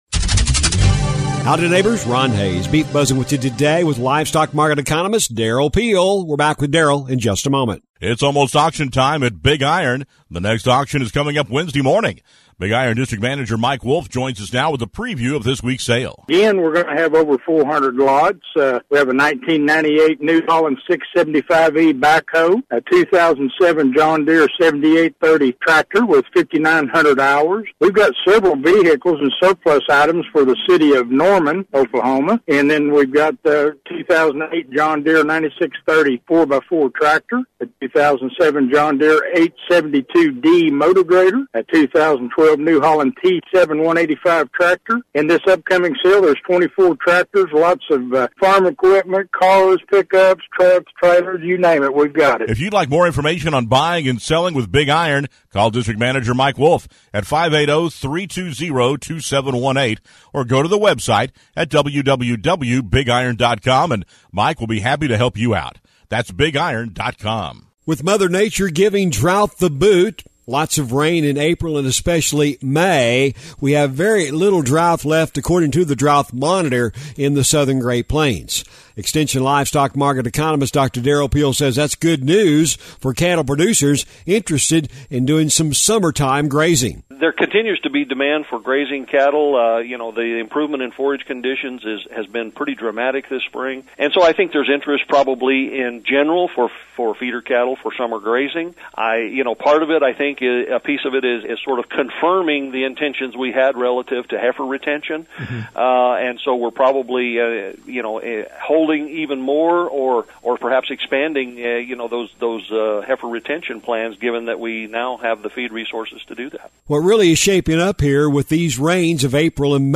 The Beef Buzz is a regular feature heard on radio stations around the region on the Radio Oklahoma Network- but is also a regular audio feature found on this website as well.